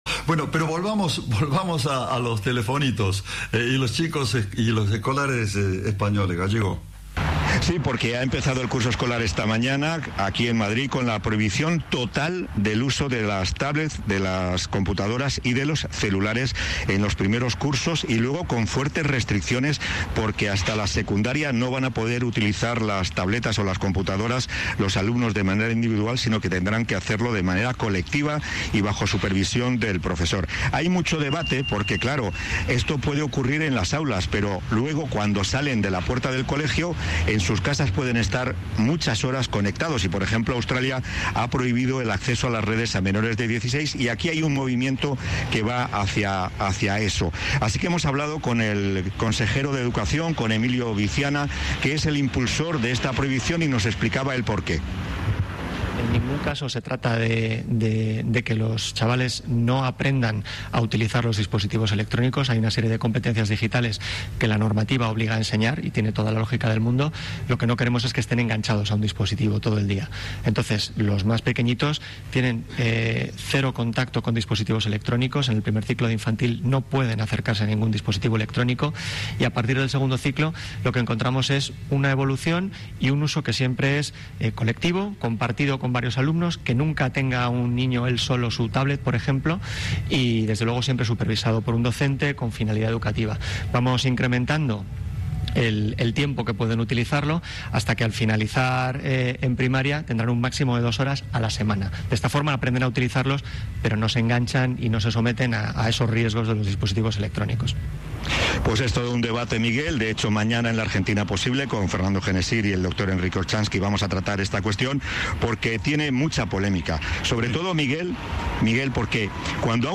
Emilio Viciana, consejero de educación y promotor de esta normativa, explicó que "en ningún caso se trata de que los estudiantes no aprendan a utilizar los dispositivos electrónicos".
Informe